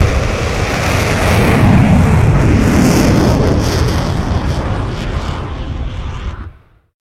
launch.ogg